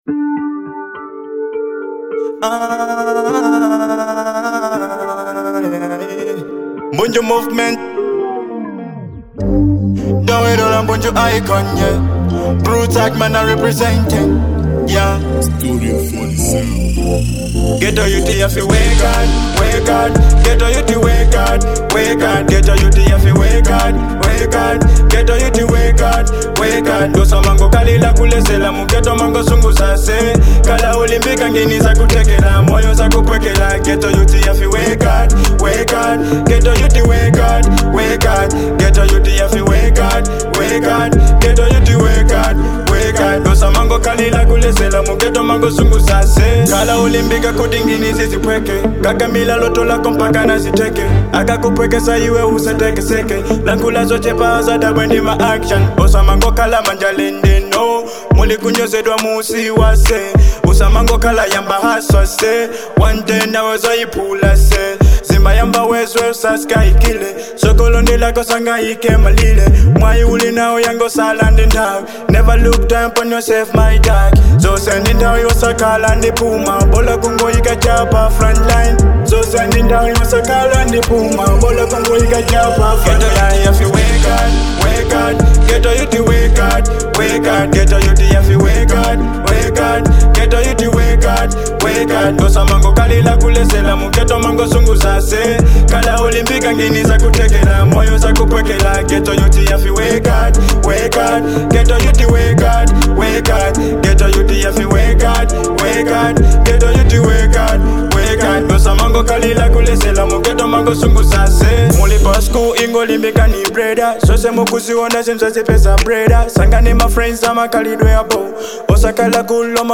Dancehall